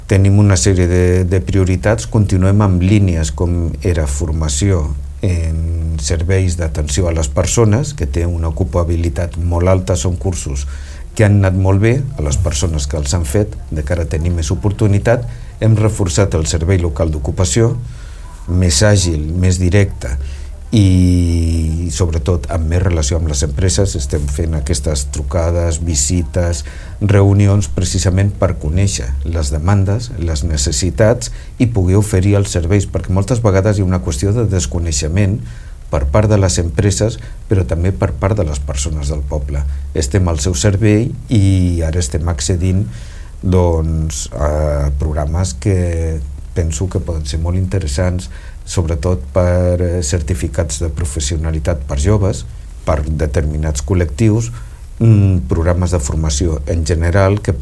El regidor d’inserció laboral i formació, Rafa Delgado insisteix en la necessitat de la formació per trobar feina.